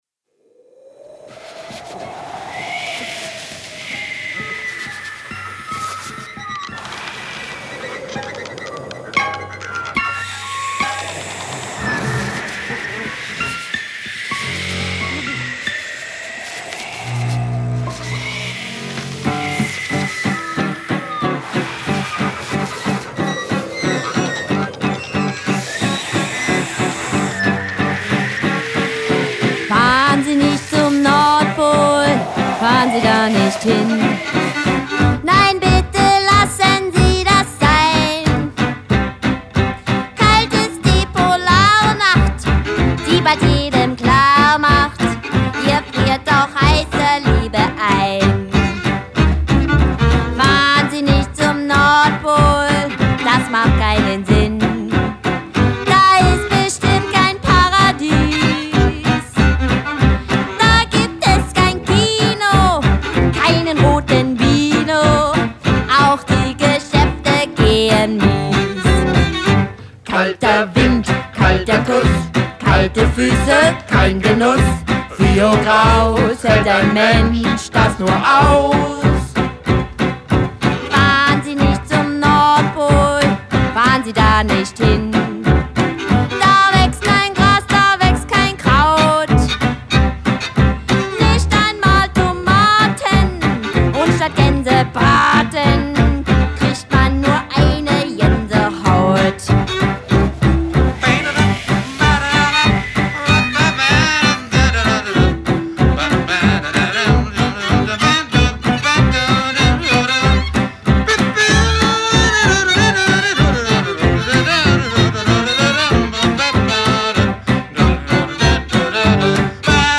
Swing Band
Jazz Trio für Events buchen - Vintage Jazz Band